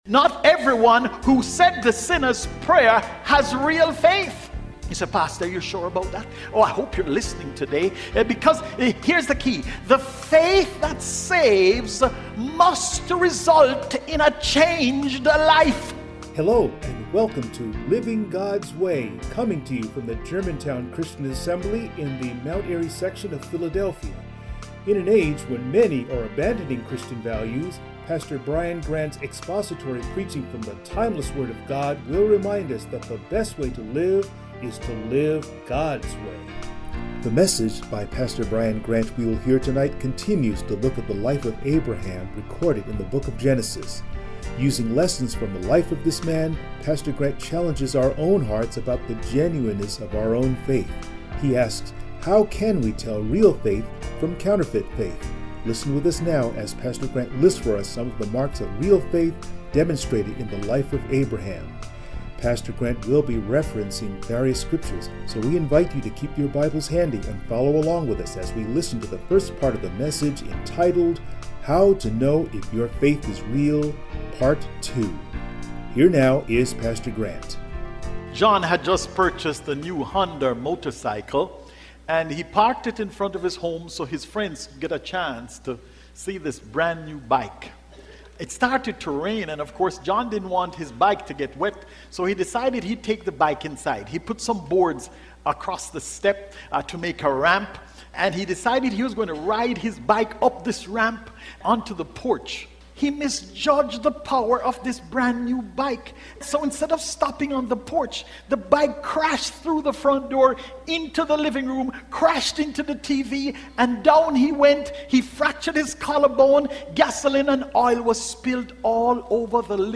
Abraham Service Type: Sunday Morning Preacher